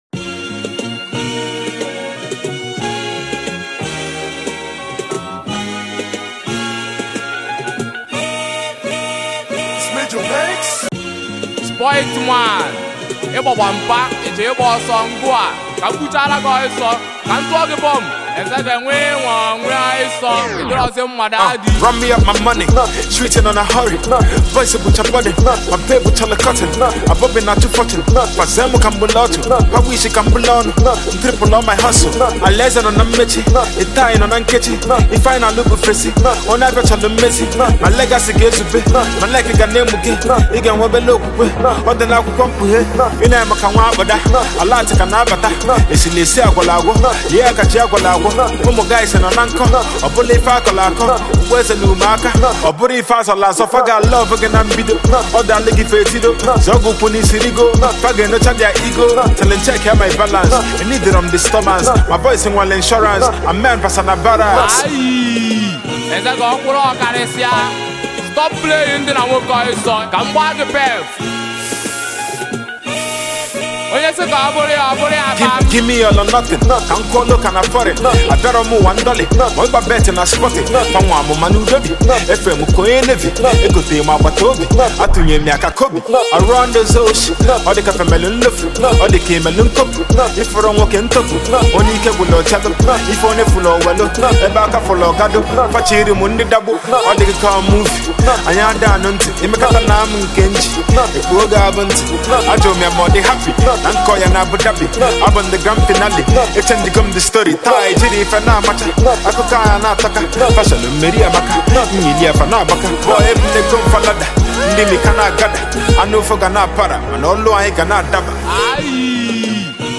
serves as the intro for this Album
consists of hypes that will get you in the mood